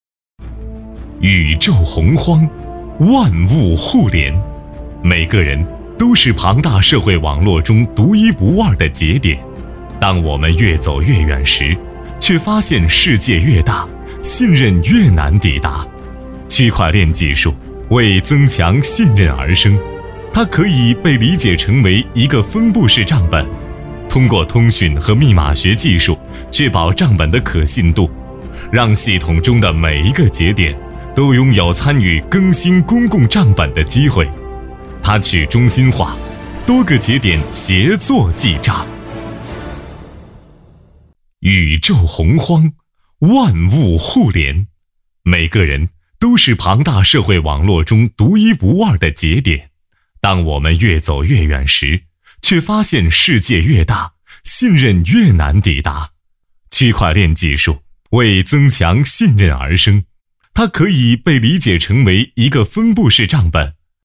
擅长：专题片 广告
特点：大气 品质